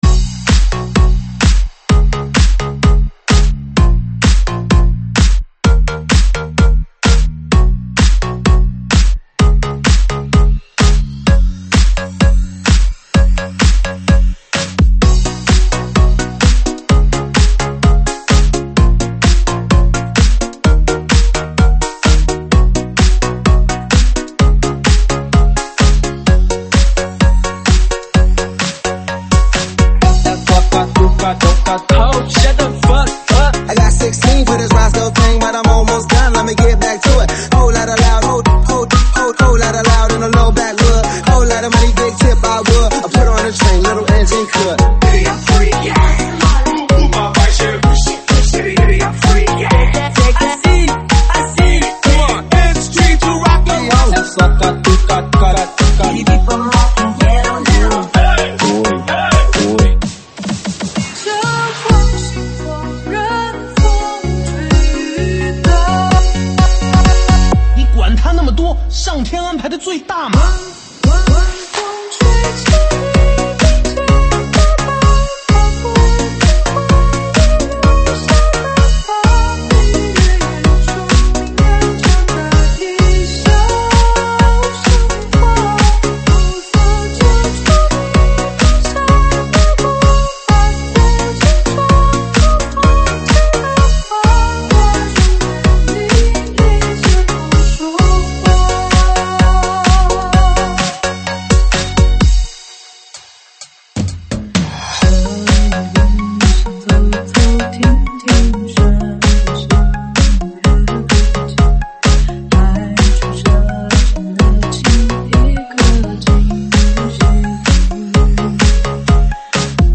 舞曲类别：ProgHouse